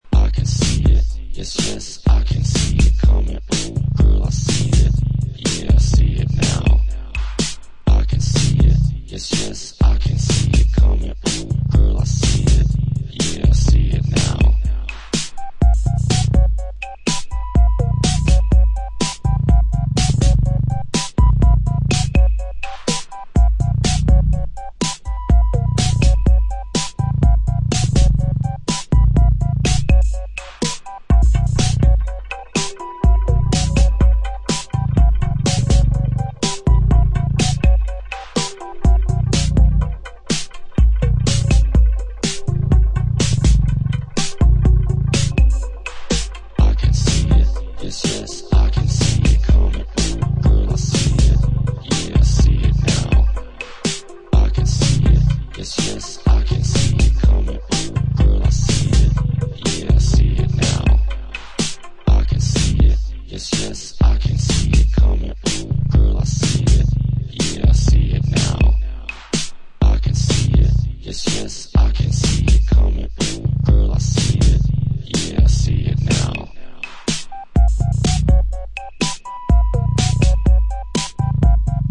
Sweet athmospherical tracks on this US. label.
Electro